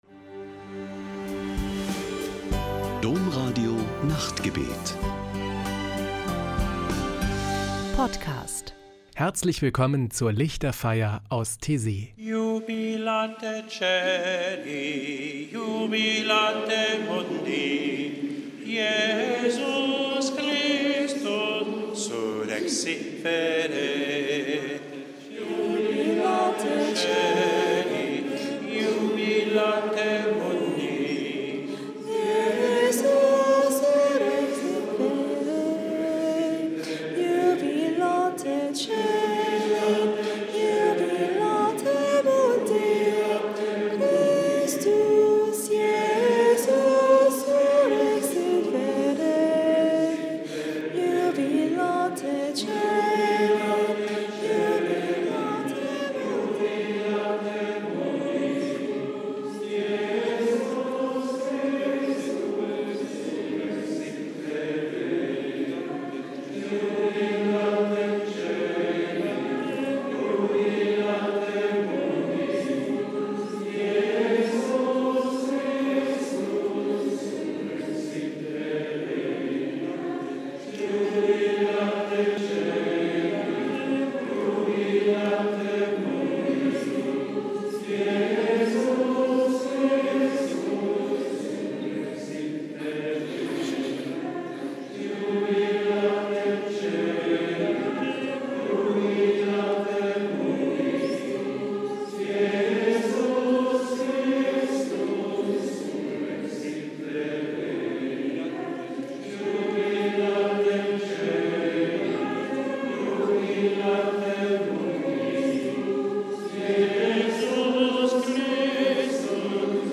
Ein Höhepunkt jede Woche ist am Samstagabend die Lichterfeier mit meditativen Gesängen und Gebeten.